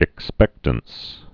(ĭk-spĕktəns)